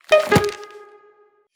mailclose.wav